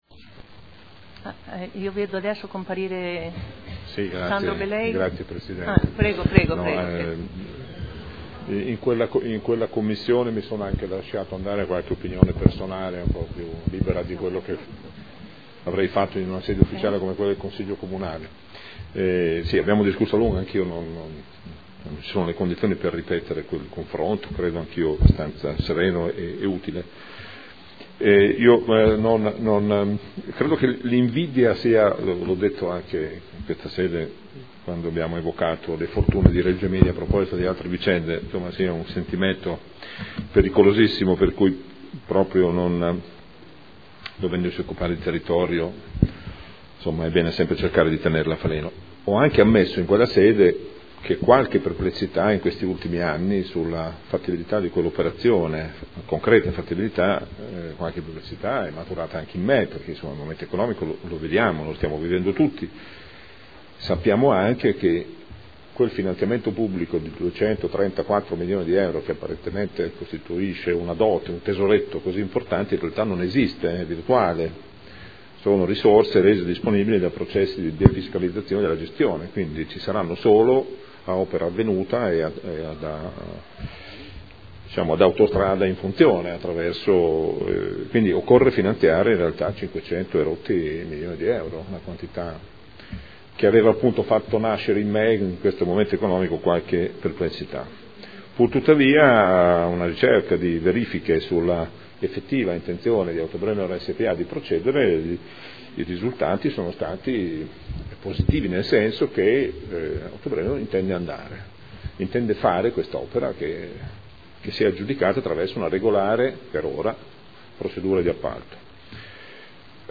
Gabriele Giacobazzi — Sito Audio Consiglio Comunale
Dibattito su Ordini del Giorno e Mozione relativi alla bretella Campogalliano-Sassuolo